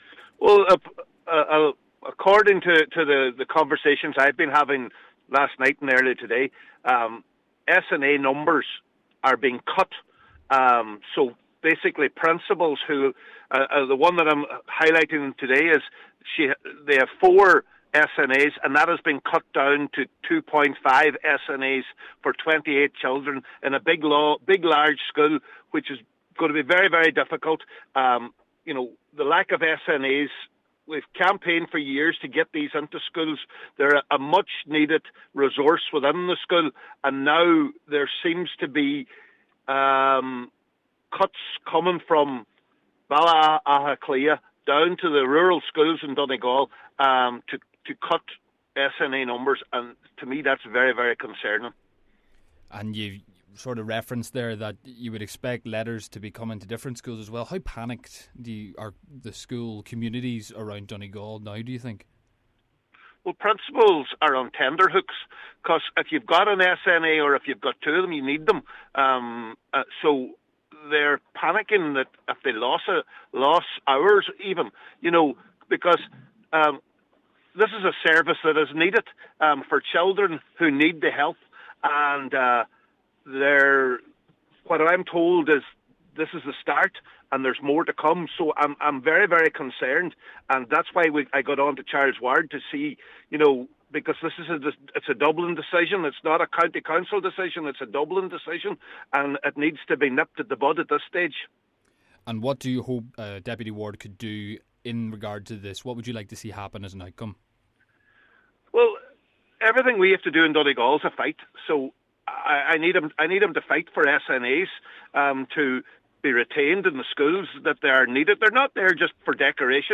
The 100% Redress representative has said that SNAs were fought for and any decrease must be stopped: